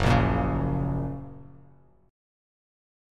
F# Chord
Listen to F# strummed